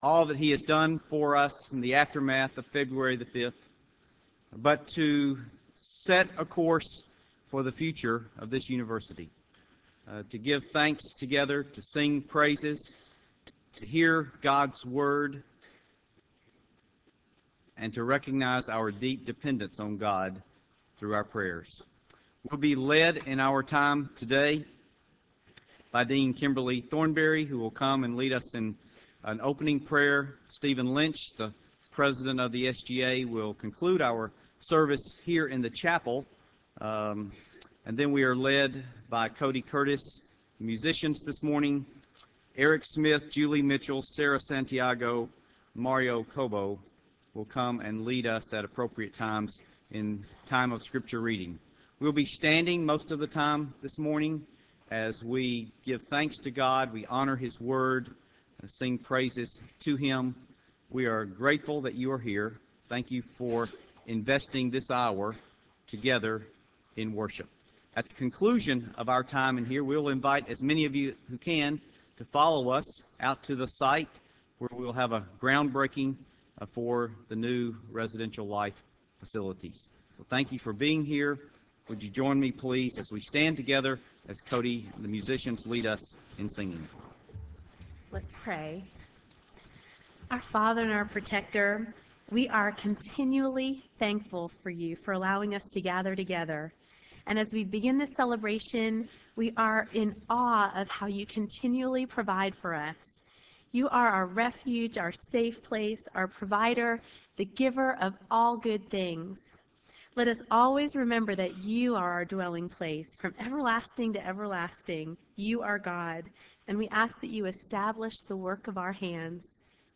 Campus-wide Worship Service: Dorm Groundbreaking